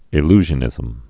(ĭ-lzhə-nĭzəm)